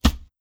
Close Combat Attack Sound 4.wav